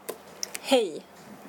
こんにちは(KONNICHIWA) Hello. Hej (ヘイ)